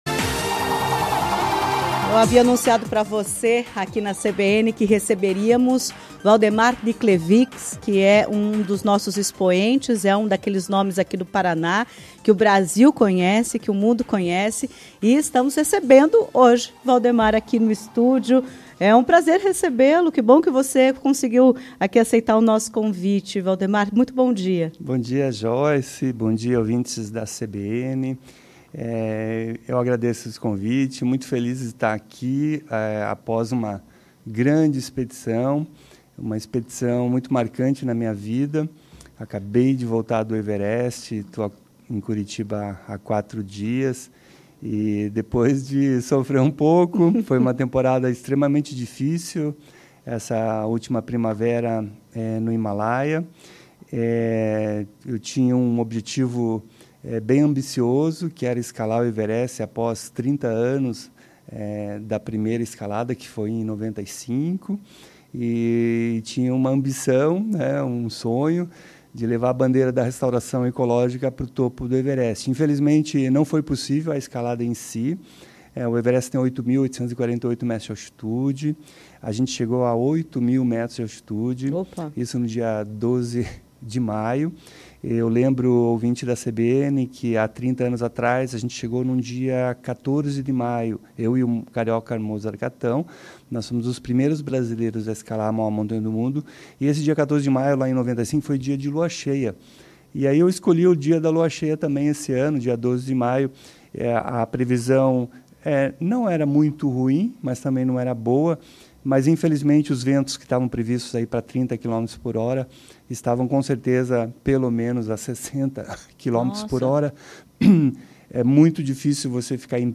Em entrevista à CBN Curitiba, ressaltou que “cada um tem seu Everest”. O dele, a partir de agora, é a Reserva Natural do Alpinista, em Campo Largo, na Região Metropolitana de Curitiba.